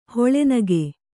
♪ hoḷe nage